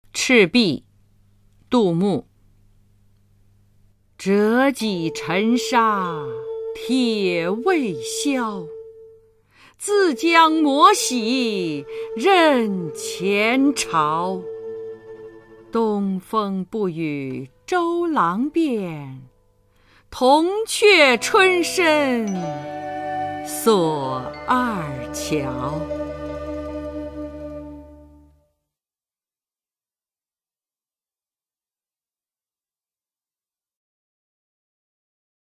[隋唐诗词诵读]杜牧-赤壁（女） 古诗文诵读